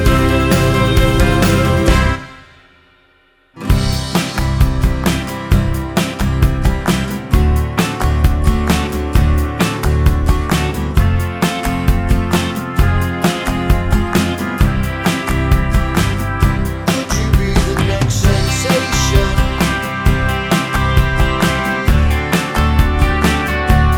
With Extra Backing Vocals Jazz / Swing 4:09 Buy £1.50